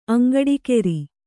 ♪ aŋgaḍikēri